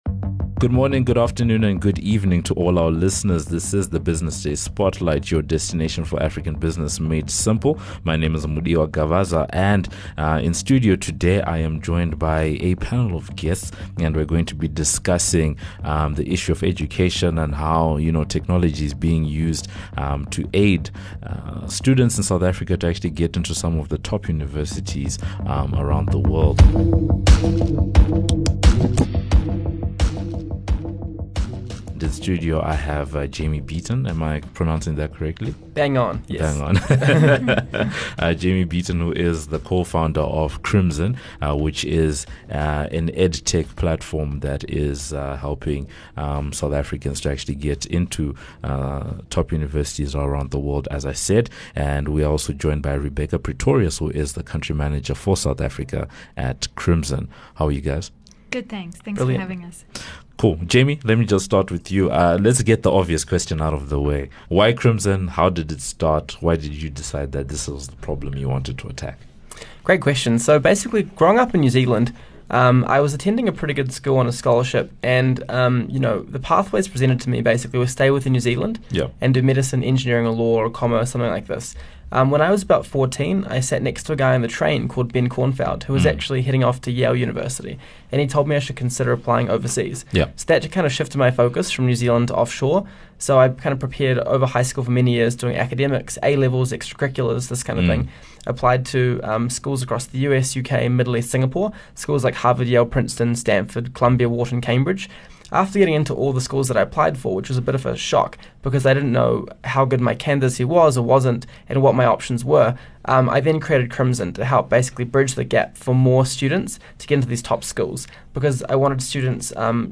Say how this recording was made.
is joined in studio